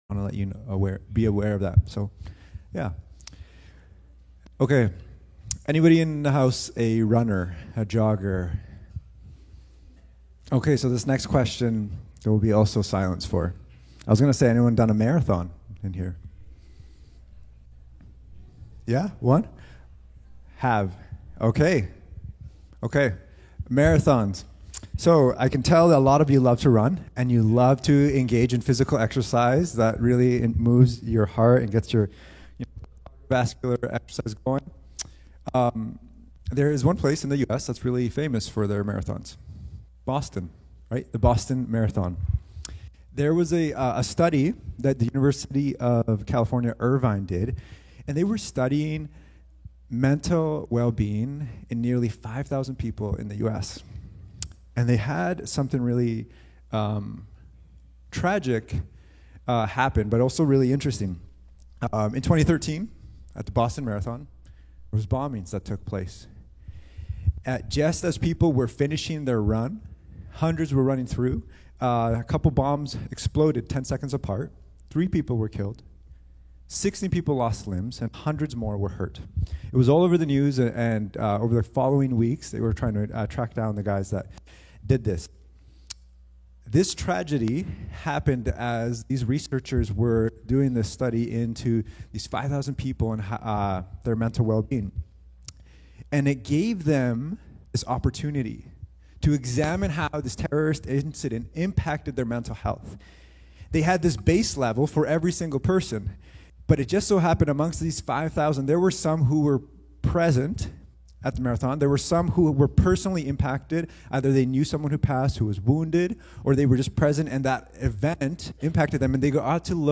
Cascades Church Sermons